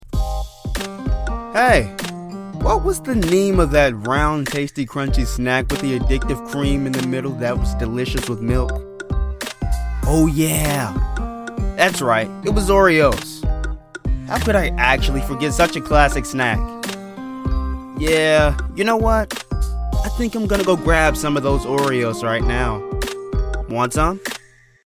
Commercial sample #1
Southern English, British English, General north American English , African American/Midwest
Teen
Young Adult